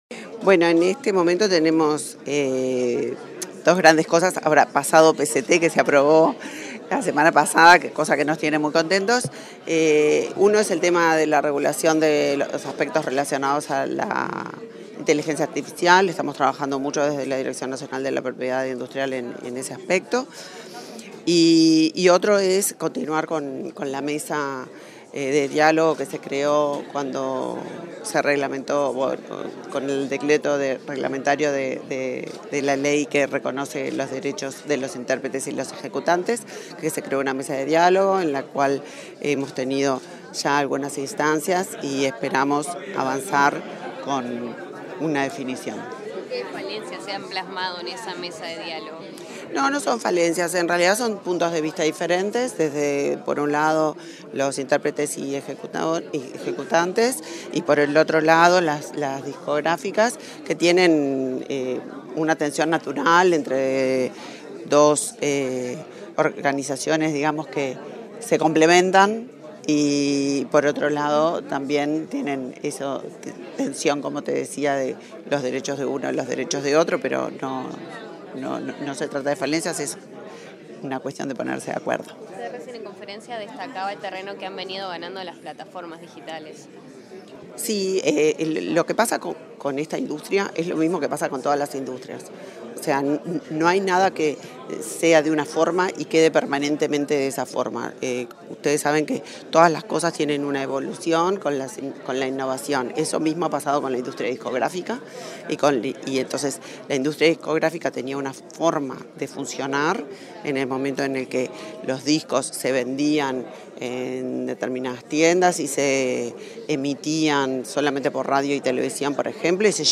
Declaraciones de la ministra de Industria, Energía y Minería, Elisa Facio
Facio prensa.mp3